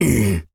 Male_Grunt_Hit_04.wav